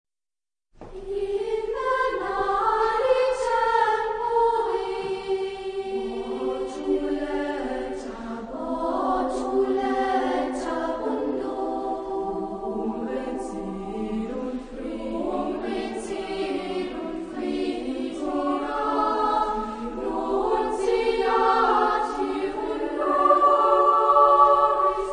Genre-Style-Form: Madrigal ; Secular
Type of Choir: SSAA  (4 women voices )
Soloist(s): Sopran (1) / Alt (1)  (2 soloist(s))
Tonality: free tonality